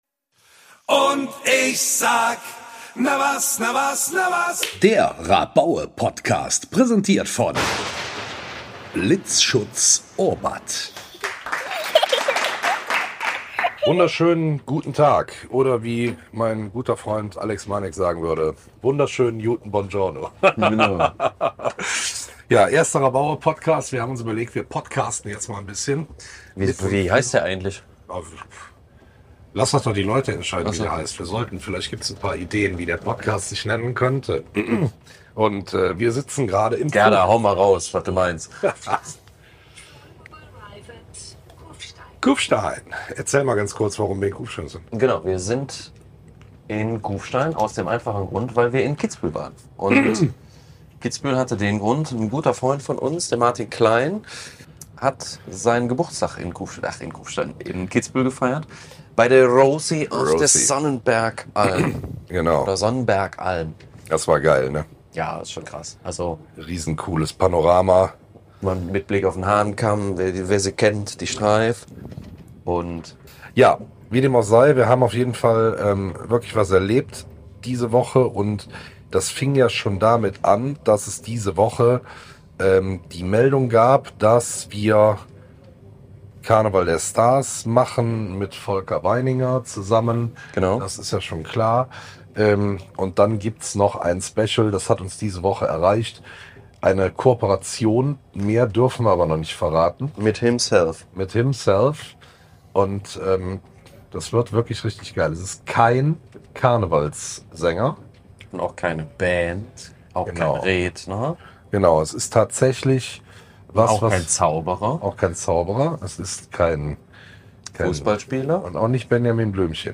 Ehrlich und spontan – genau so, wie man die RABAUE kennt und liebt.